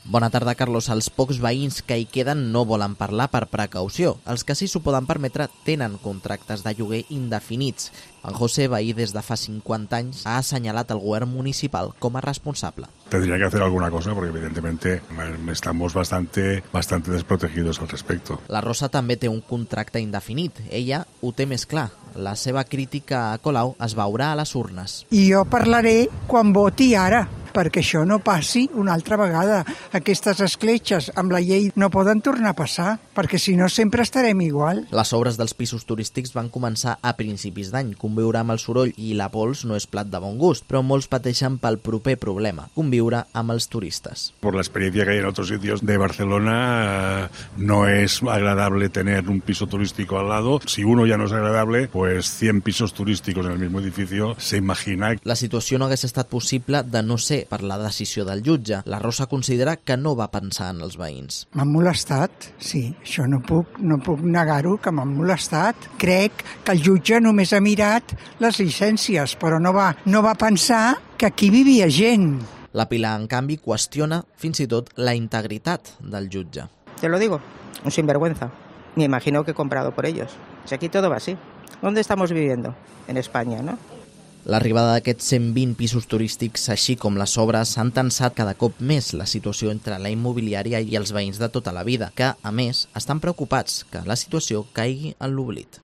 crónica
frente al edificio